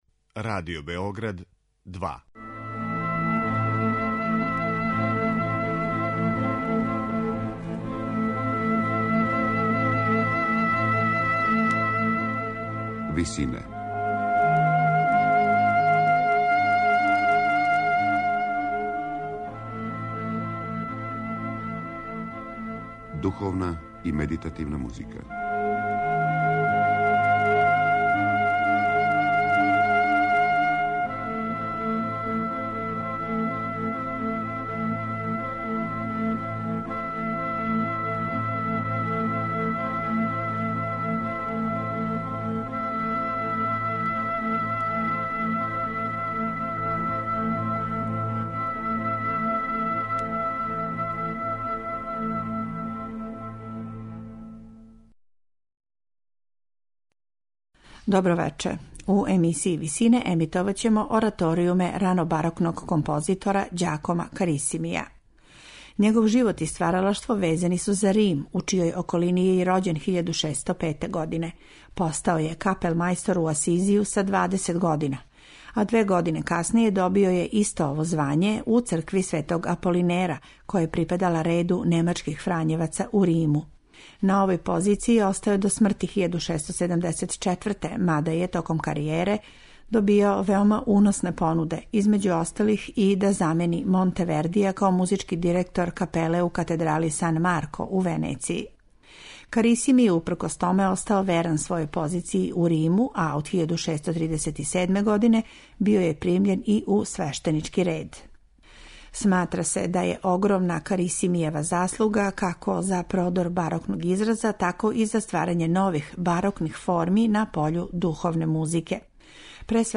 У емисији Висине слушаћете ораторијуме Ђакома Карисимија, једног од твораца овог жанра у 17. веку.
Извођачи су чланови ансамбла "Лирски таленти", којима диригује Кристоф Русе.